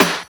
13 SNARE  -L.wav